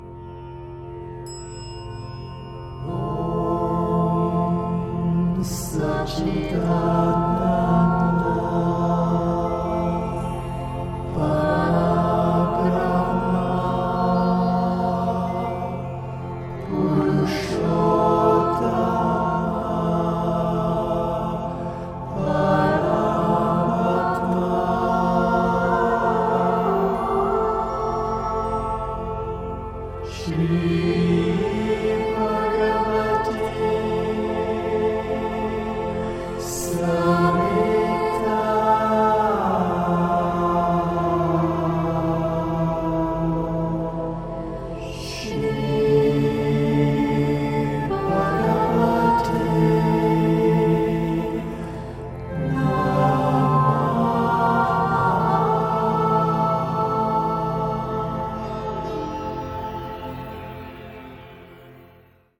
Sacred meditative, devotional Vedic Sanskrit mantra
lovely angelic voice
solfeggio healing frequencies.